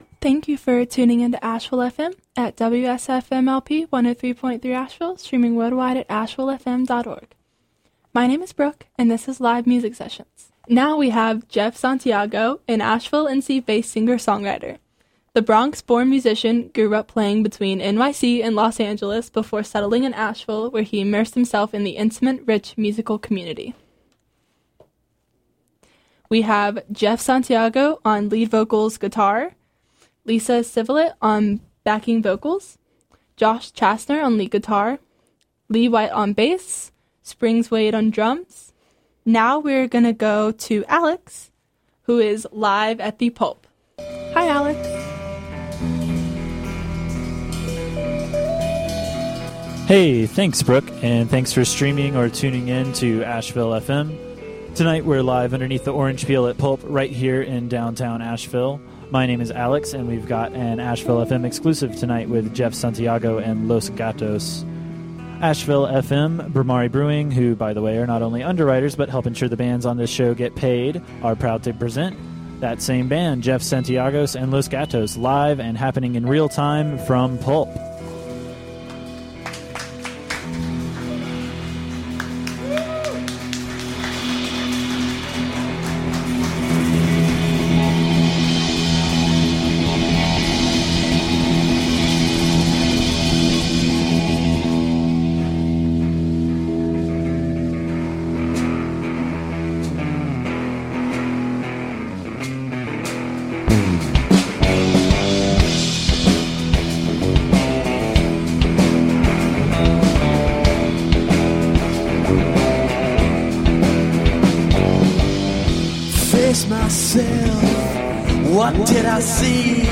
Live from The Pulp
Recorded during safe harbor – Explicit language warning
Their songs sway, they funk, they rock and they pop.
Lead Vocals/Guitar
Backing Vocals
Lead Guitar
Bass
Drums